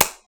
clap1.wav